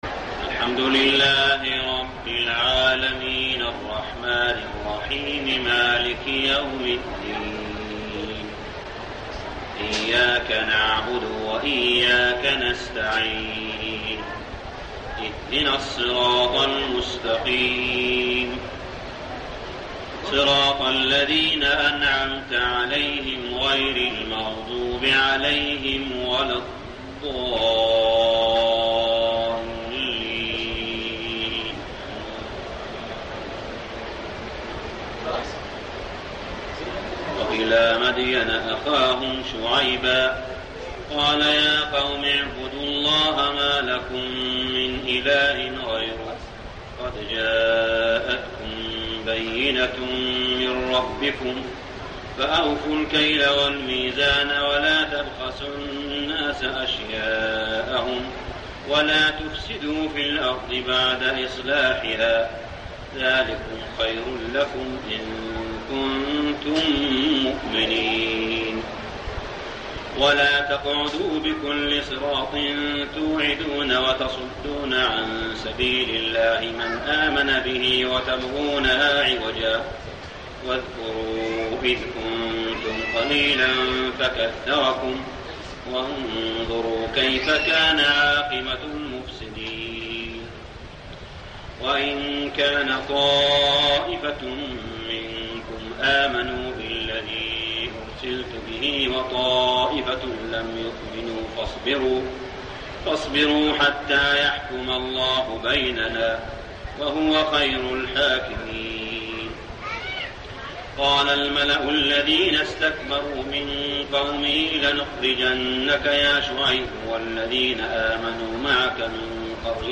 صلاة التراويح ليلة 9-9-1409هـ سورة الأعراف 85-170 | Tarawih Prayer Surah Al-A'raf > تراويح الحرم المكي عام 1409 🕋 > التراويح - تلاوات الحرمين